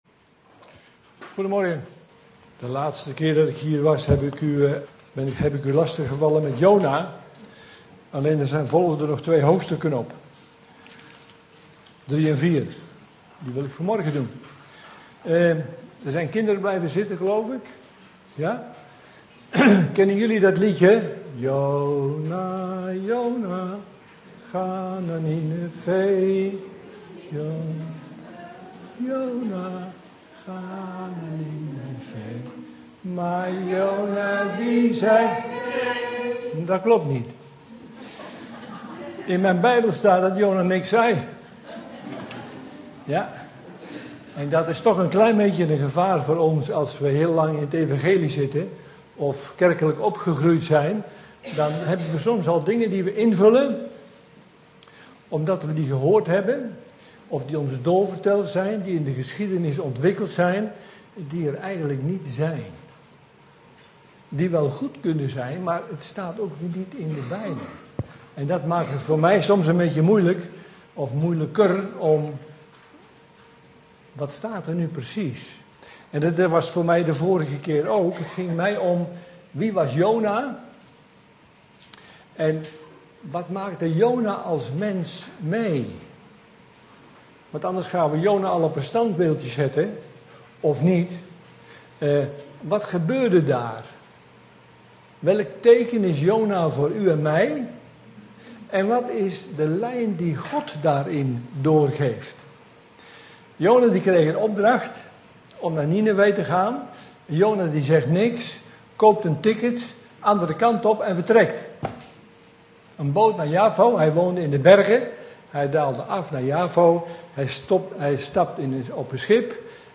In de preek aangehaalde bijbelteksten (Statenvertaling)